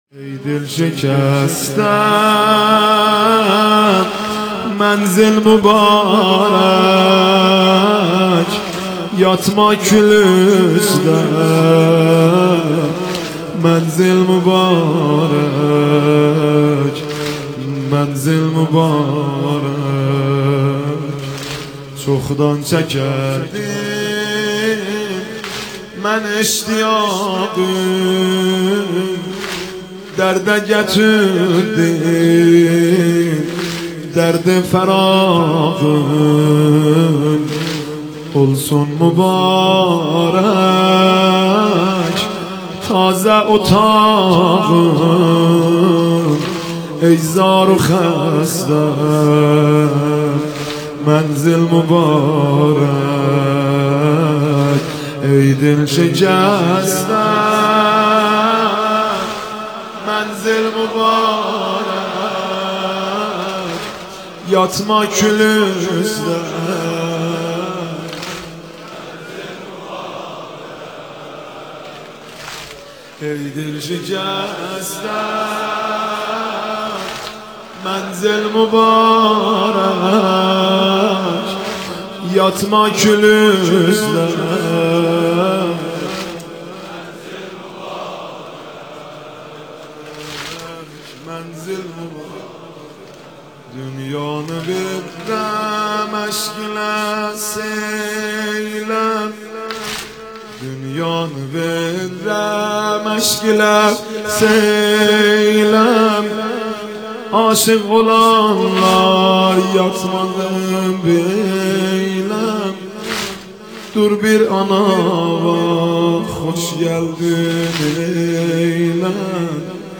دسته : موسیقی ملل